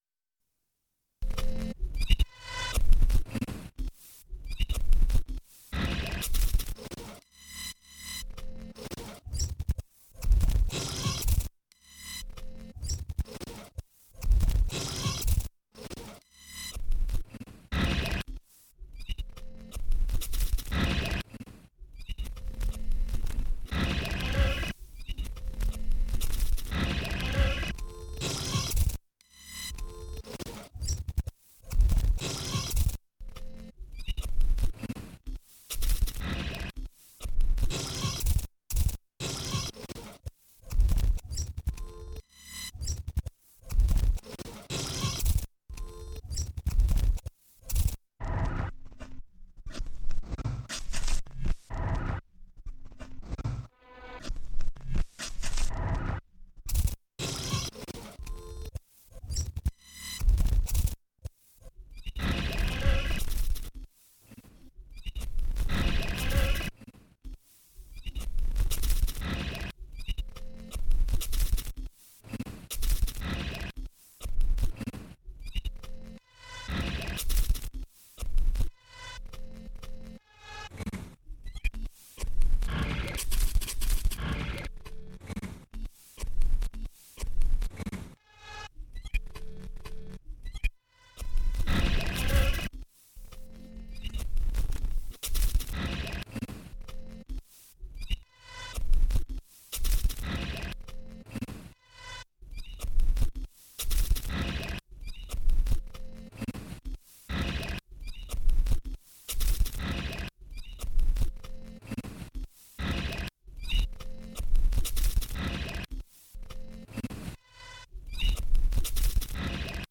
Digitalisiert von MiniDisk.